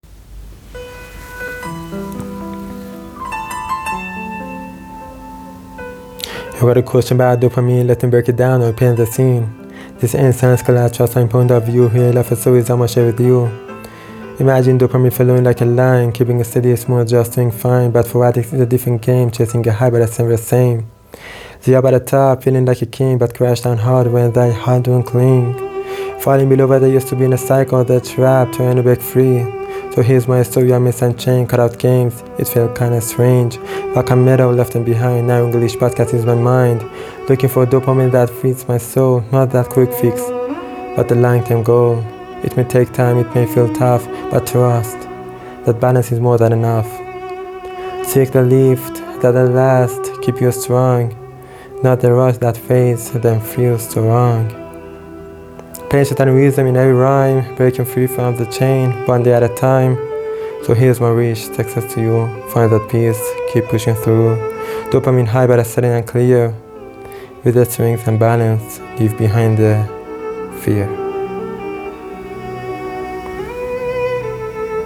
پ ن: میدونم اهنگ زمینه با متن اهنگ که رپ و تند بود جور نبود ولی به دلیل موضوع دوپامین مجبور شدم اروم بزارم:melting_face: